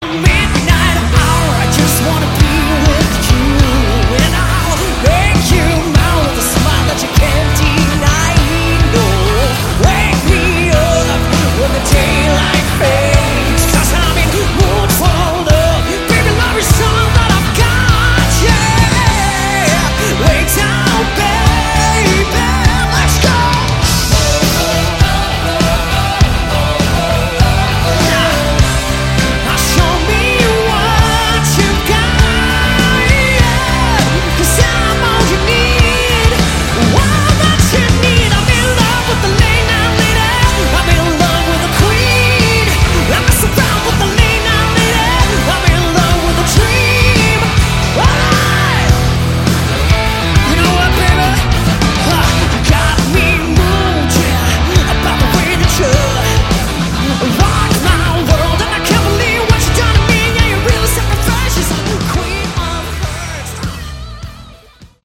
Category: Hard Rock
keyboards, vocals
bass, vocals
lead vocals, acoustic guitar